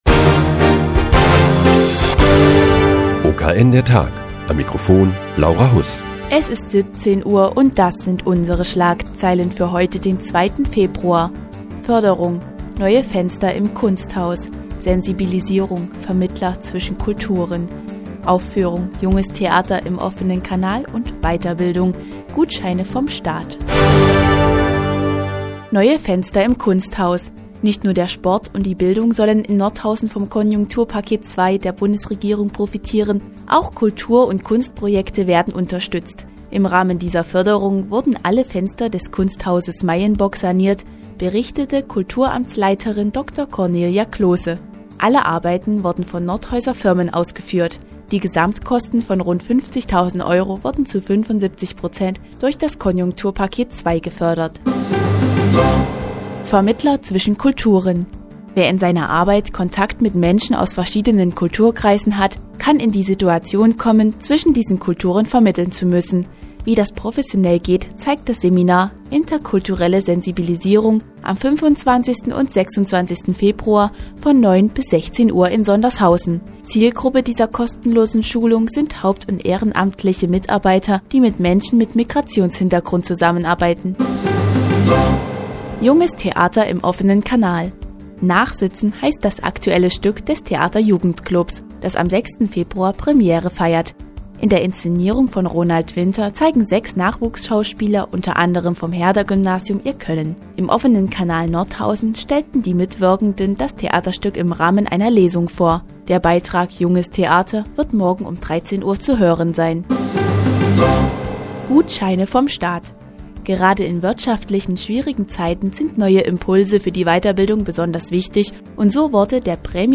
Die tägliche Nachrichtensendung des OKN ist nun auch in der nnz zu hören. Heute heute geht es um die Sanierung des Kunsthauses Meyenburg und das aktuelle Stück "Nachsitzen" des Theaterjugendclubs.